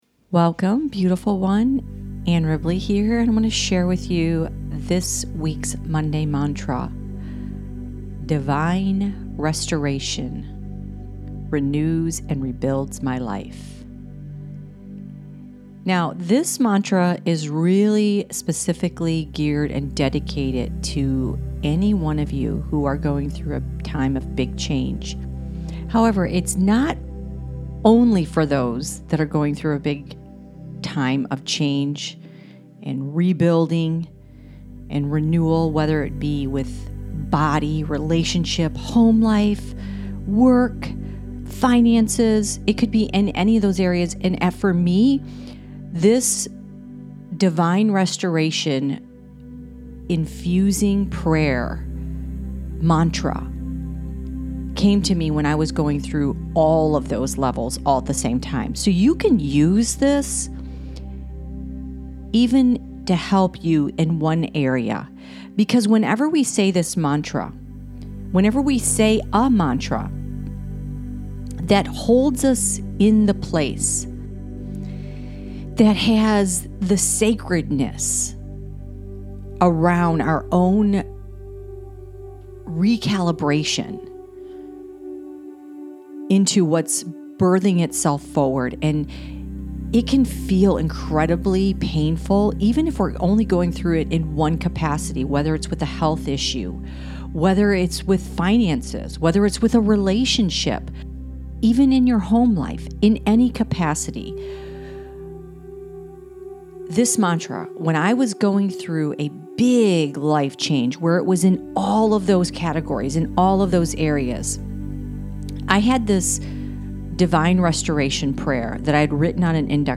Divine Restoration Mantra Audio Intention